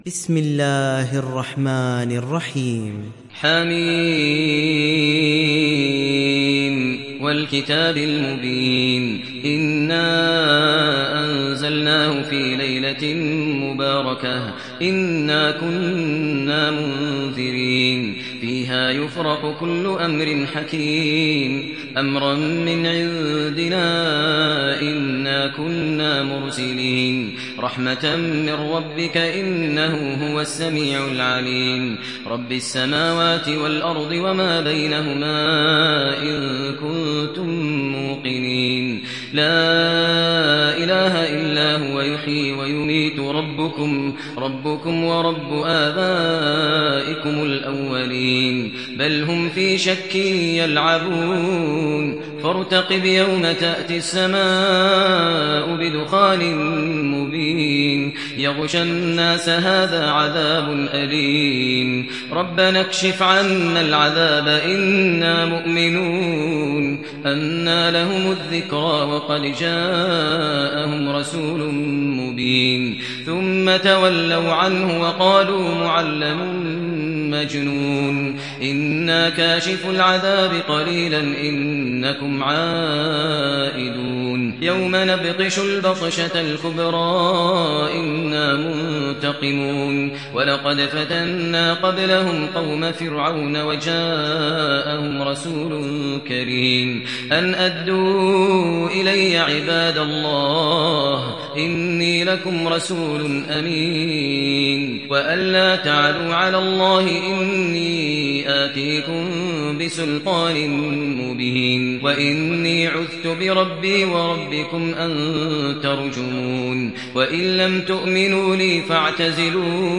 دانلود سوره الدخان mp3 ماهر المعيقلي روایت حفص از عاصم, قرآن را دانلود کنید و گوش کن mp3 ، لینک مستقیم کامل